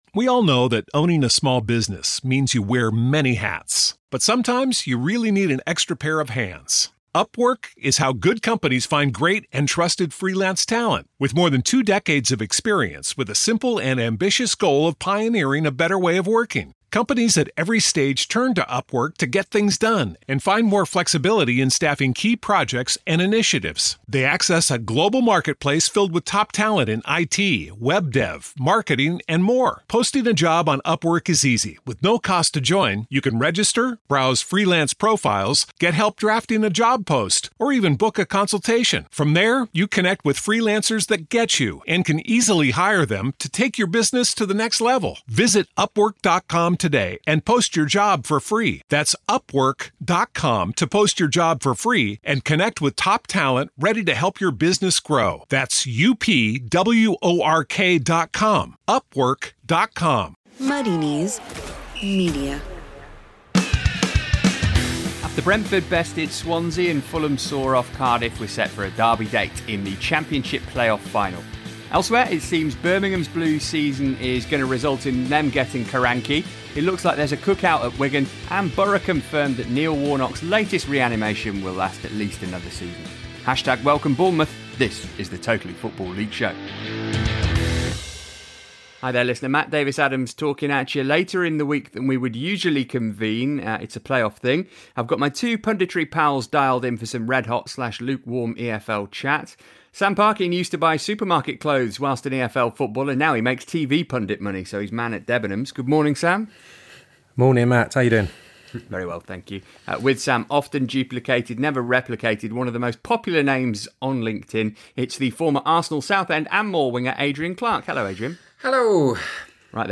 PART 1b - Interview